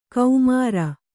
♪ kaumāra